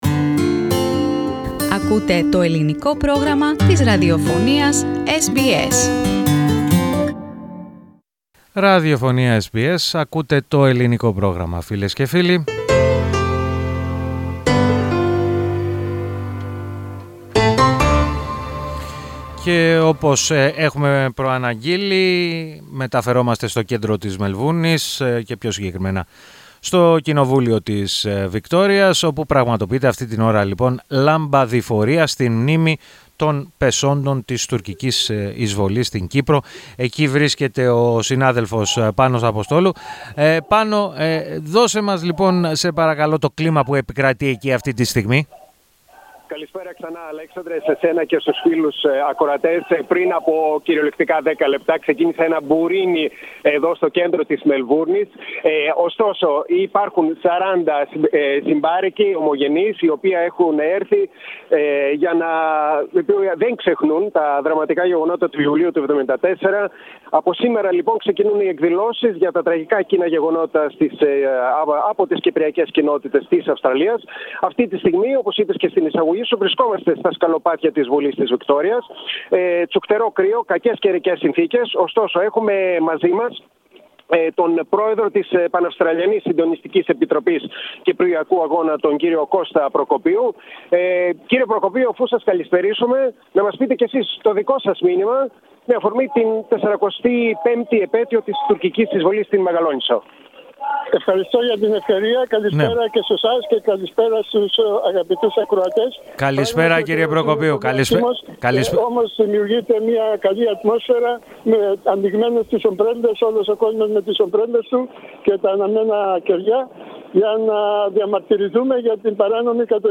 On the occasion of the 45th Anniversary of Turkish invasion in Cyprus SEKA Victoria organised candlelight vigil at Victorian Parliament's steps on Friday the 12th of July.
At Victorian Parliament's steps in the occasion of the 45th anniversary of the Turkish invasion of Cyprus.